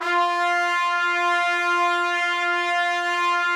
brass / trumpet-section / samples / F4.mp3